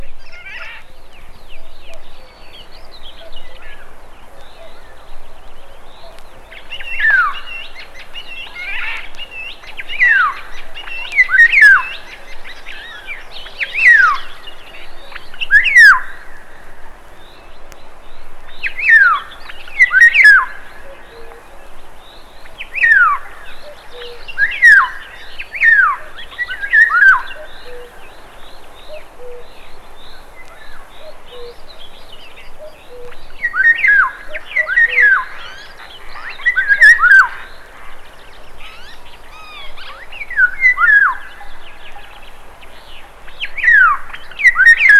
彼の研究の一端を資料化、本人の解説がとベルリンやミュンヘンでフィールドレコーディングして採集された様々な野鳥の声が収録された1枚。
Other, Non-Music, Field Recording　Germany　12inchレコード　33rpm　Stereo